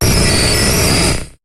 Cri de Magnéton dans Pokémon HOME.